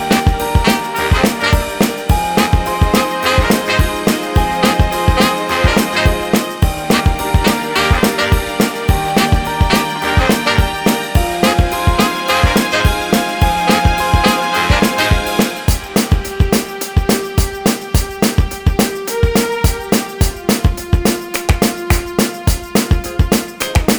No Bass Or Guitar Pop (2000s) 3:38 Buy £1.50